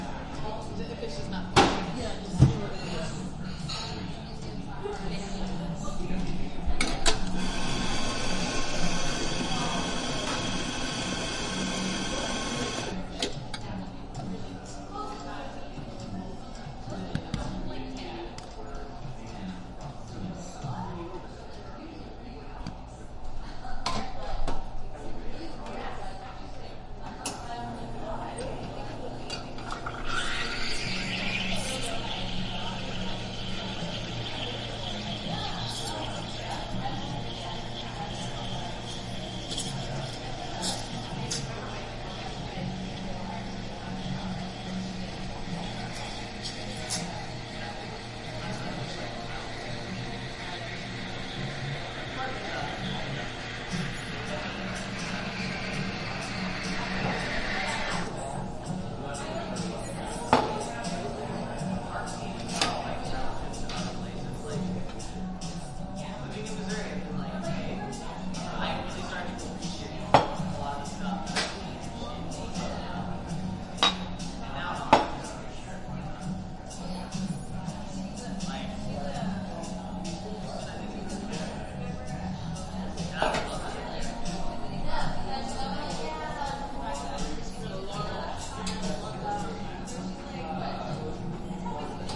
描述：这是在科罗拉多州博尔德的Folsom St.咖啡公司录制的咖啡师准备咖啡饮料的录音。 录音在MacBook Pro电脑上，由森海塞尔ME67麦克风插入Sound Devices MixPre.
标签： 咖啡师 卡布奇诺咖啡 咖啡 咖啡 店铺
声道立体声